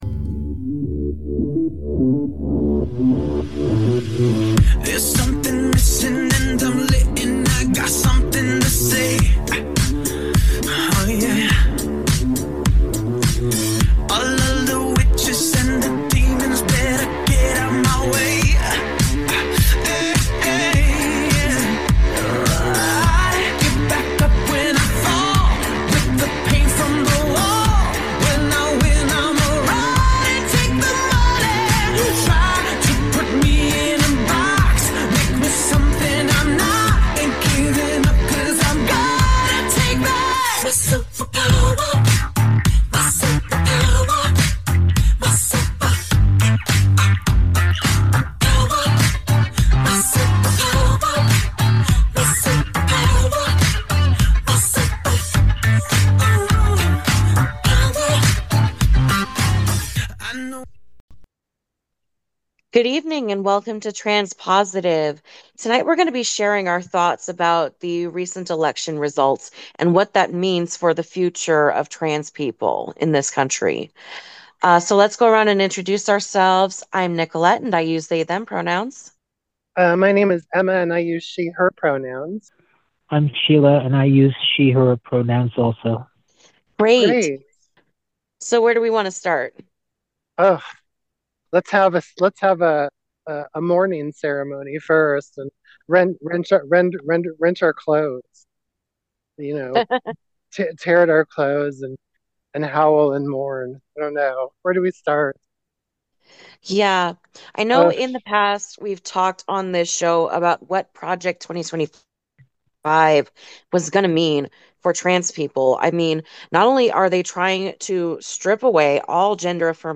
Transpositive is a biweekly program in Portland exploring themes important to those who identify as Transgender, Gender Non-Binary, and Gender Non-Conforming. Transpositive tries to connect listeners with the ongoing activist, arts, culture, and dialogue happening in Portland and beyond.